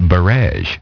Транскрипция и произношение слова "barege" в британском и американском вариантах.